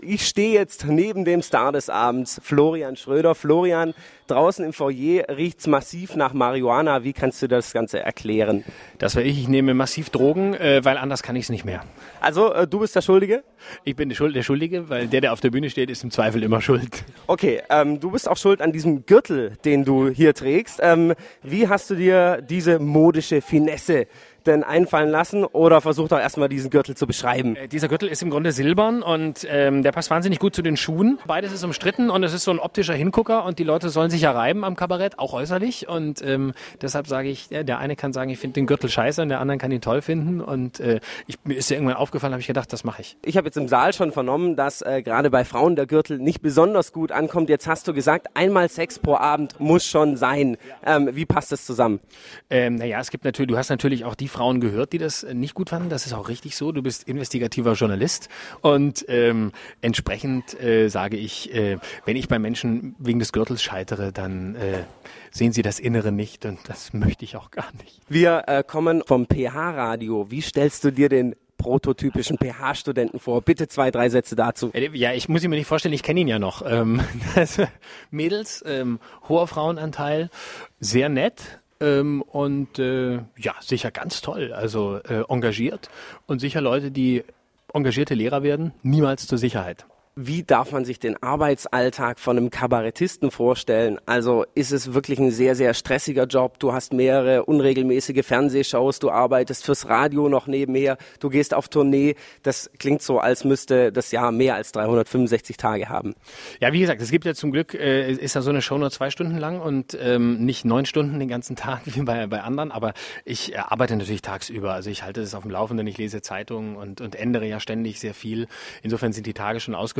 Interview mit Florian Schroeder
interview-mit-florian-schroeder.mp3